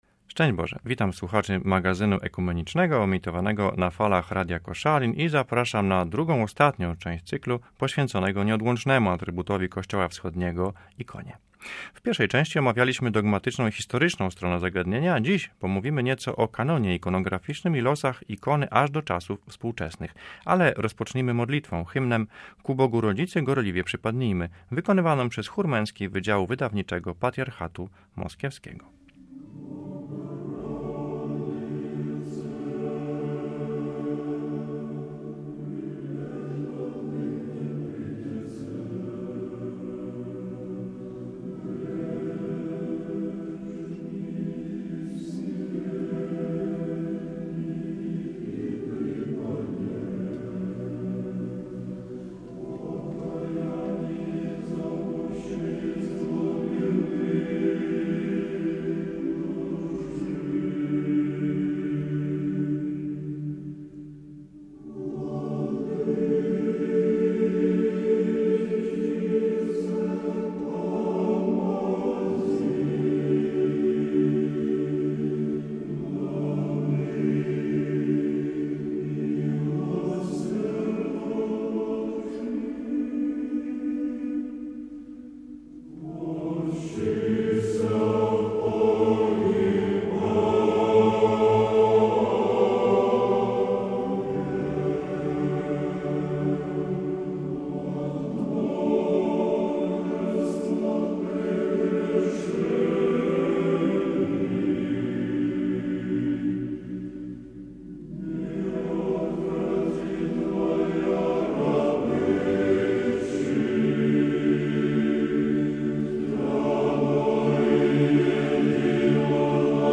Audycja